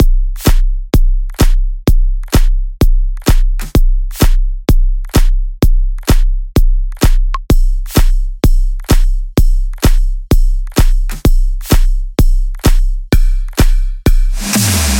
特里普勒大鼓
描述：在C调，三段式，适用于任何类型的音乐。
标签： 128 bpm Electronic Loops Drum Loops 2.52 MB wav Key : C
声道立体声